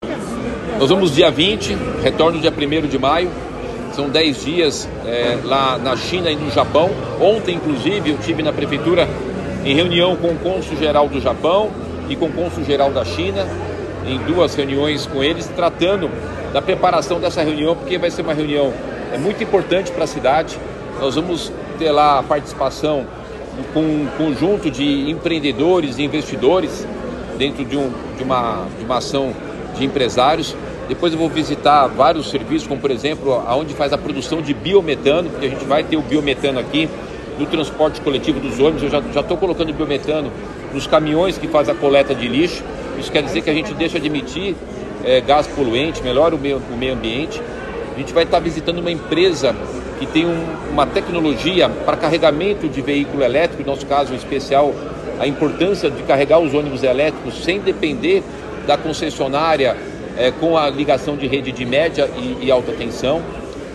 ENTREVISTA: Nunes vai a Ásia conhecer produção de biometano para ônibus, além de recarga para elétricos que dependa menos da ENEL